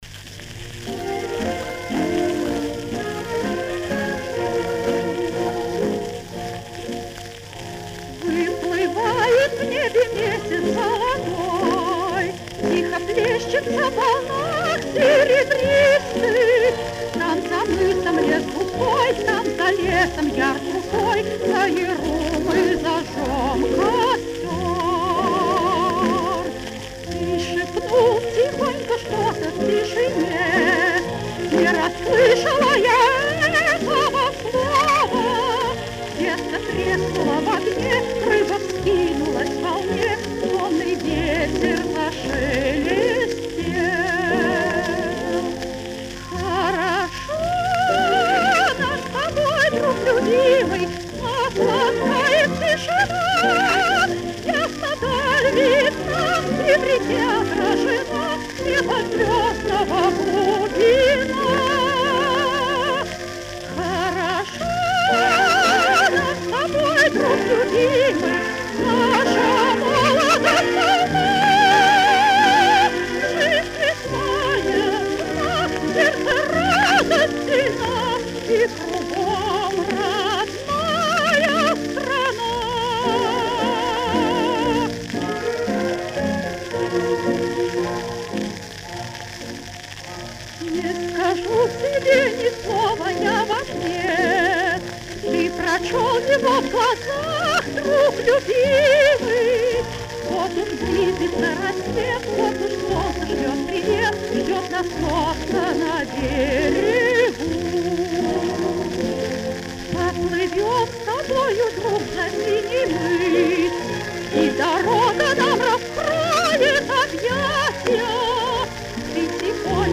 Лирико-патриотическая песня.